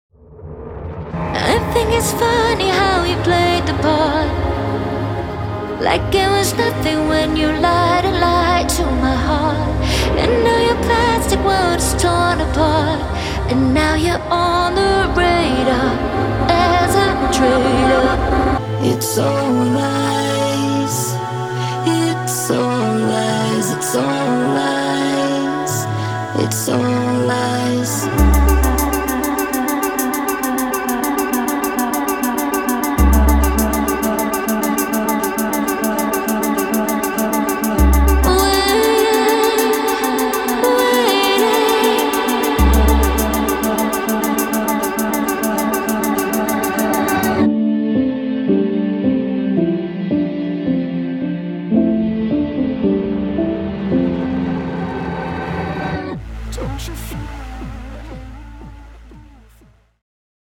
her soaring voice and pulsing club beats